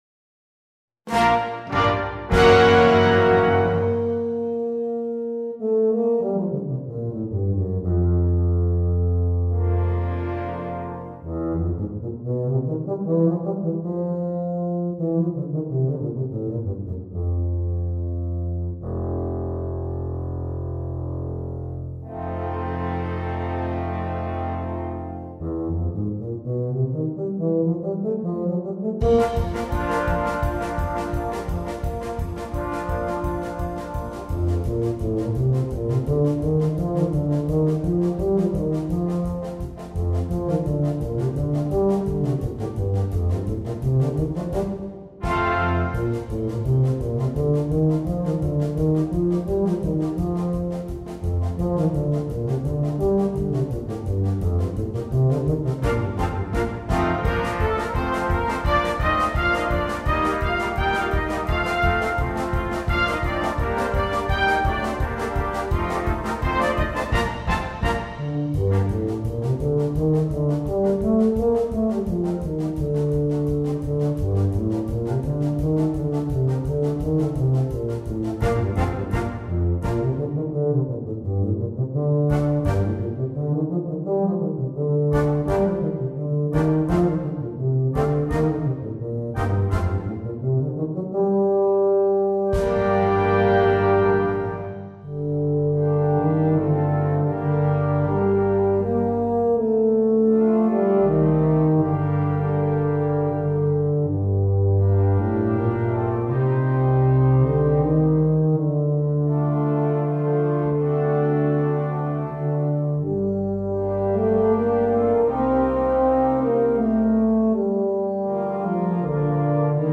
with solo instrument
Bb Bass or Eb Bass (Solo)
Entertainment